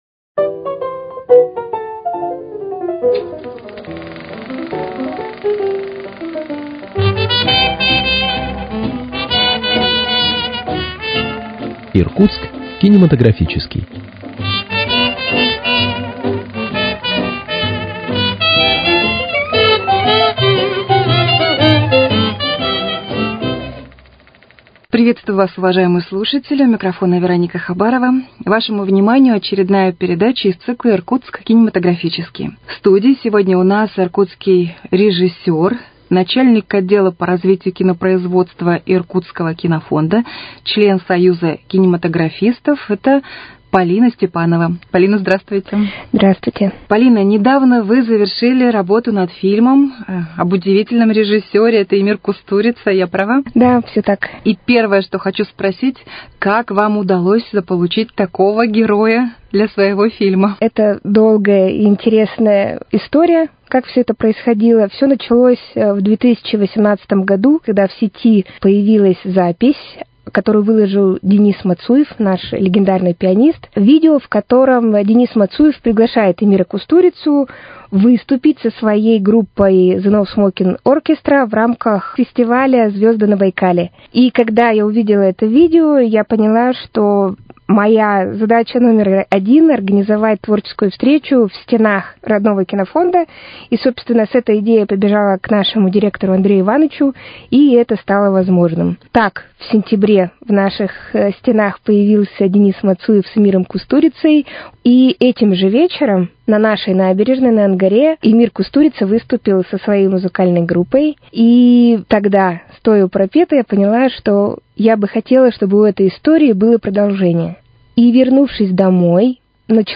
На этот раз гостем студии стала иркутский режиссер